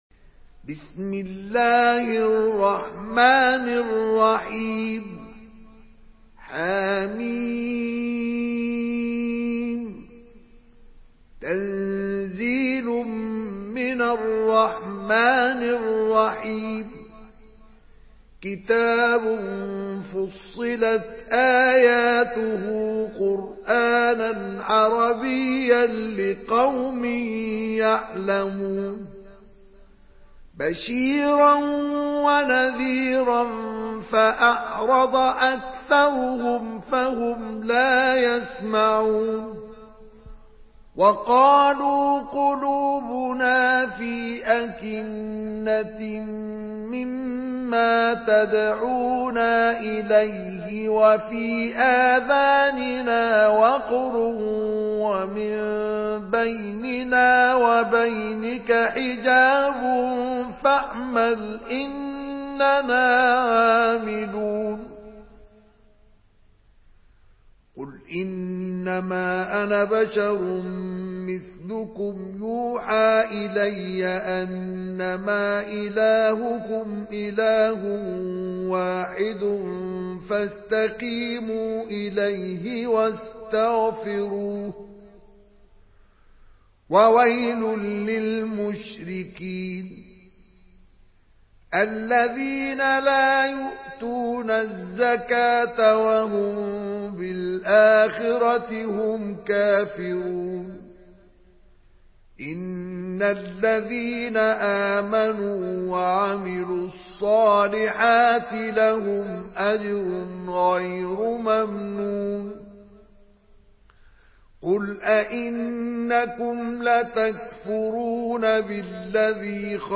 Surah ফুসসিলাত MP3 by Mustafa Ismail in Hafs An Asim narration.
Murattal Hafs An Asim